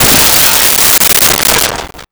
Window Shatter 04
Window Shatter 04.wav